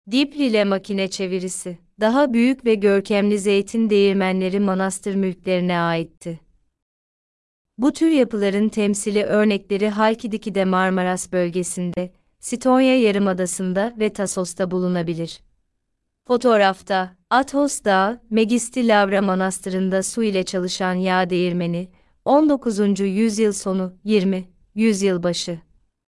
Sesli rehberli tur